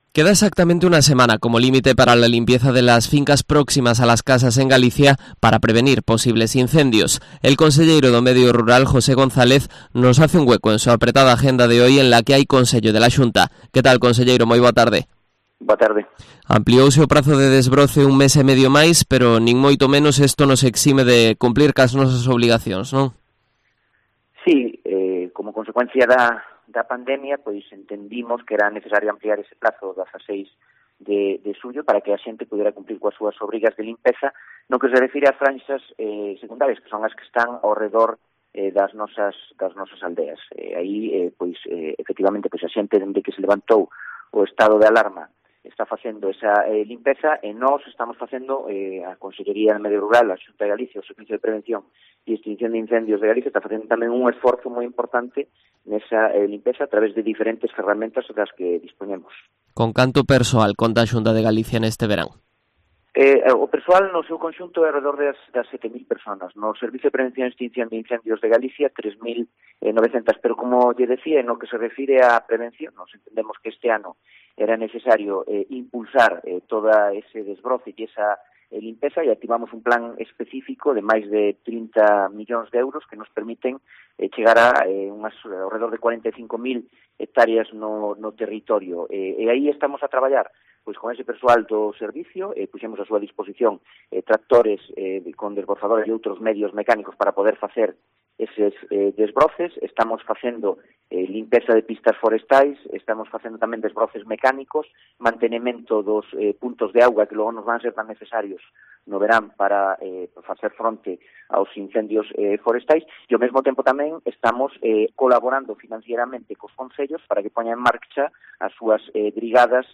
Entrevista con el conselleiro do Medio Rural, José González